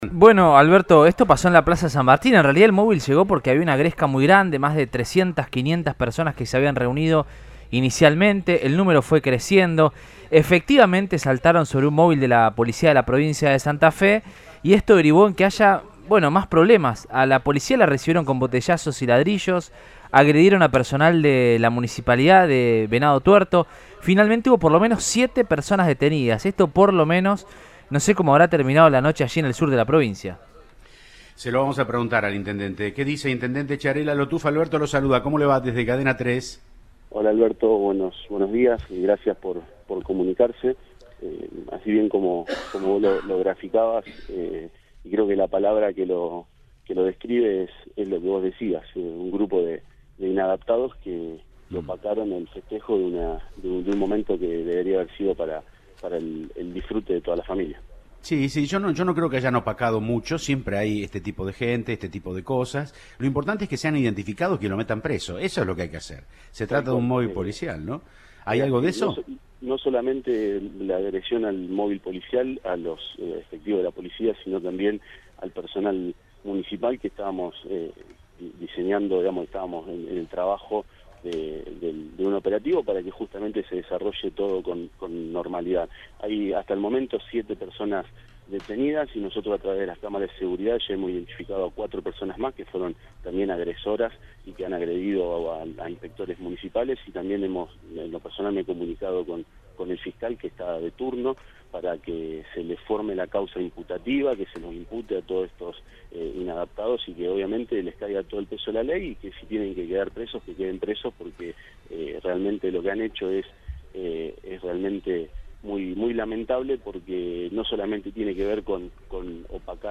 Leonel Chiarella, intendente de la ciudad de Venado Tuerto, en diálogo con Siempre Juntos de Cadena 3 Rosario confirmó que “7 personas fueron detenidas” este martes luego de la clasificación de la Selección Argentina a la final de la Copa del Mundo de Qatar 2022, luego de que provocaron violentos disturbios enfrentándose a agentes de policías y control de tránsito.